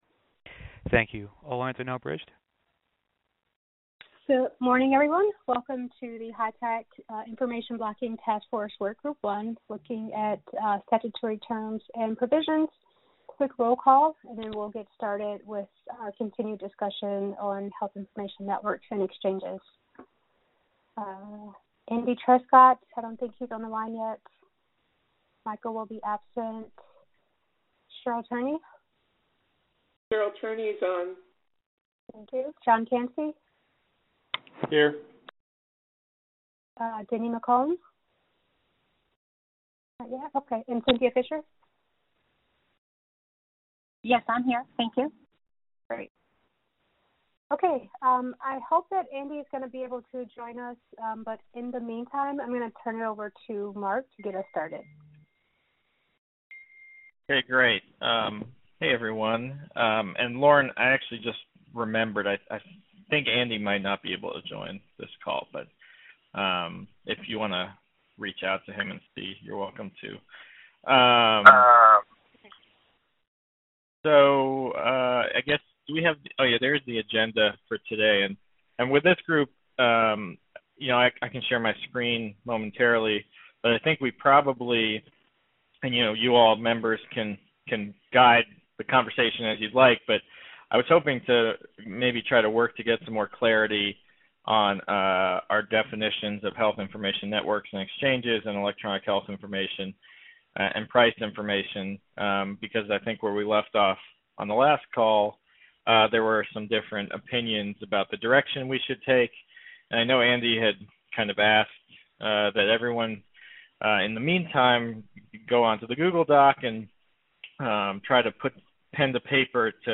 2019-03-27_IACC_group1_VirtualMeeting_Audio_0